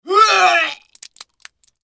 Звуки тошноты, рвоты
Звук отрывания